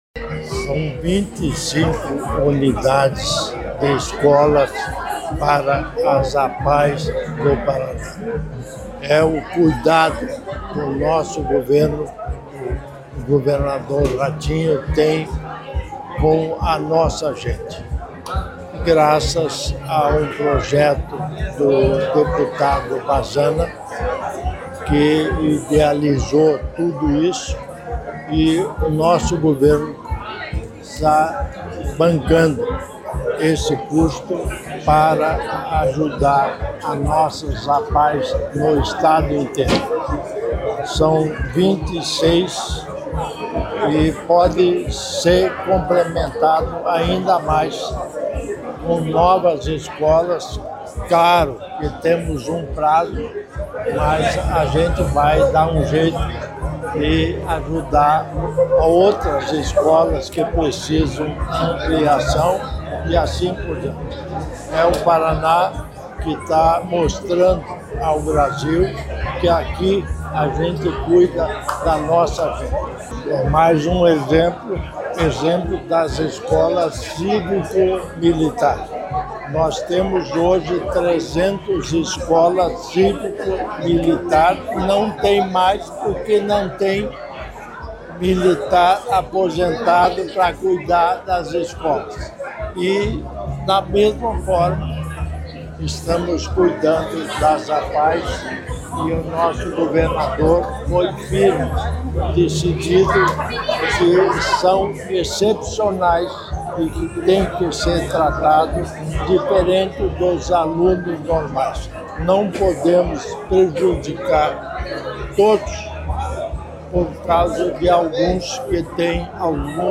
Sonora do vice-governador Darci Piana sobre a nova sede da APAE de Douradina